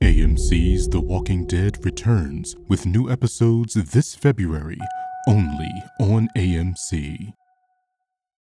TV Promo Samples
Ominous, Mysterious
TV-Promo-Demo_The-Walking-Dead.mp3